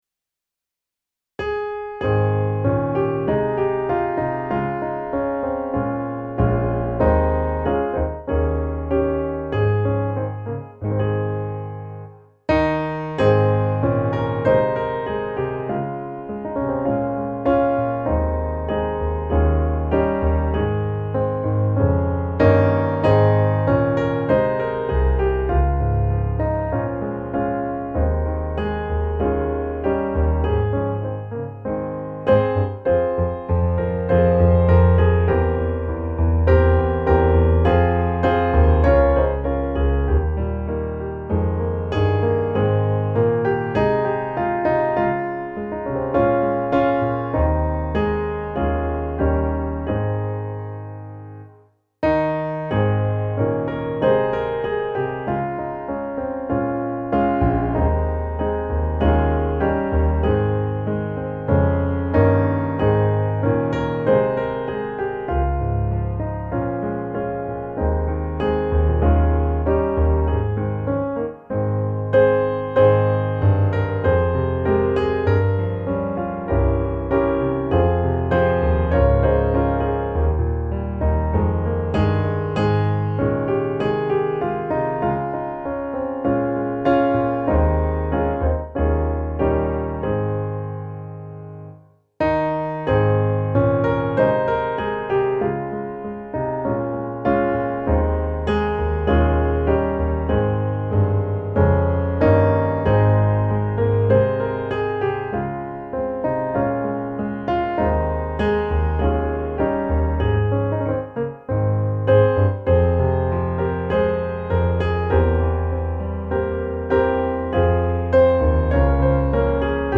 musikbakgrund
Musikbakgrund Psalm